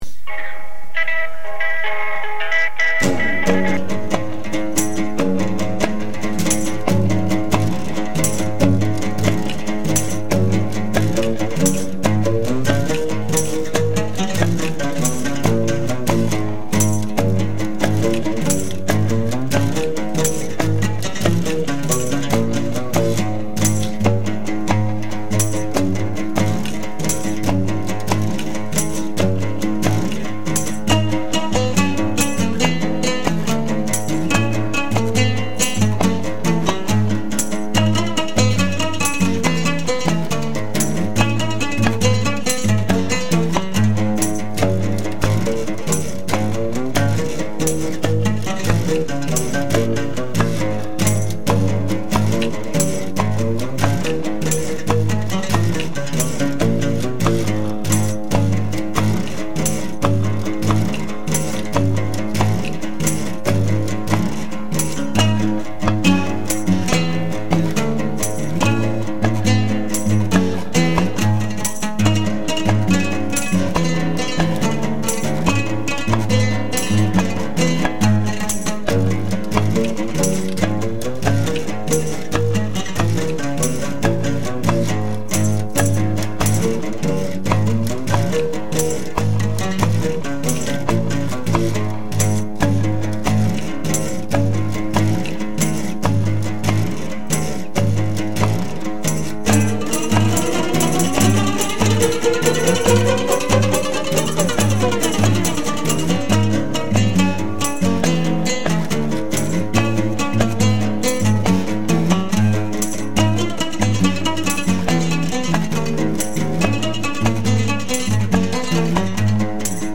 I used a fragment of a field recording from the Bamam tribe of Cameroon, and used it as an intro for my piece, which I divided into three movements.
It represents longing, mirroring the thoughts of the dinghy's occupants, shunted in the unknown (like the ancient Romans might have viewed the North Sea) with only their fears for company.